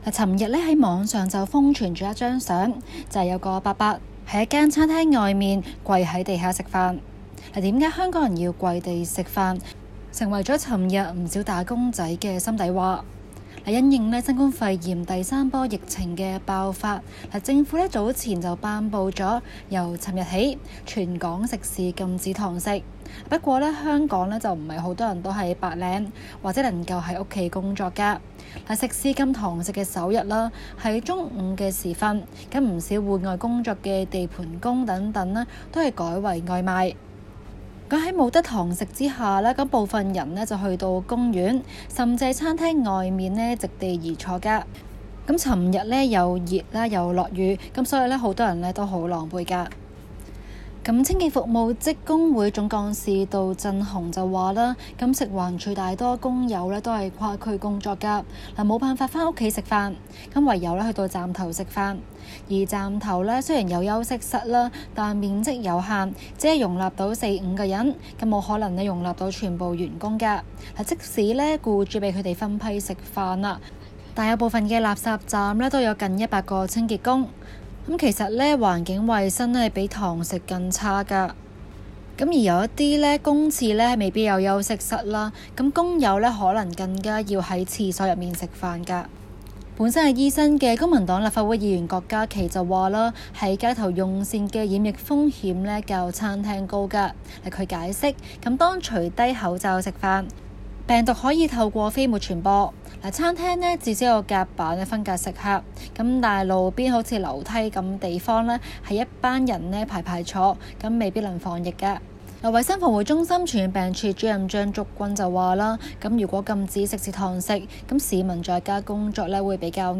今期【中港快訊 】環節報道跟進香港疫情嚴峻，禁堂食令出， 打工仔午飯難覓用飯安坐地點。